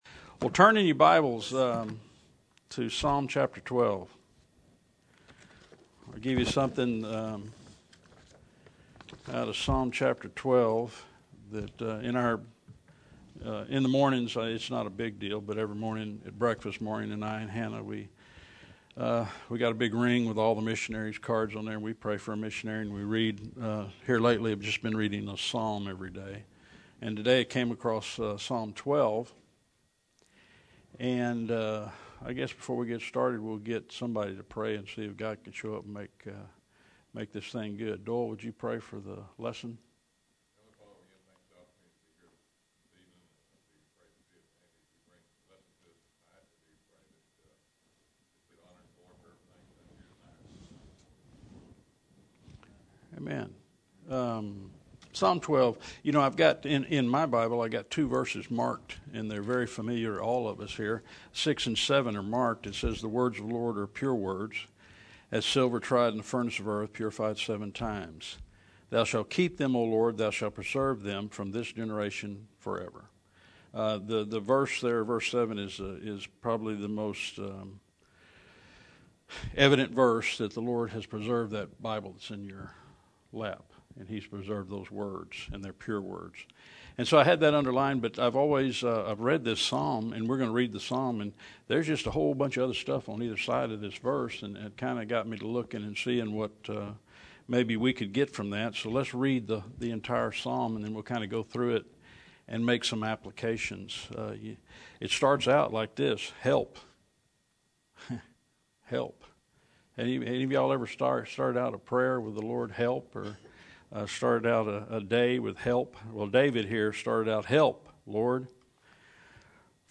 This passage has a Tribulation context, yet we can be helped by a present-day application. Here on a Wednesday night, you have just stepped out of the world around you and into a sanctuary, your church.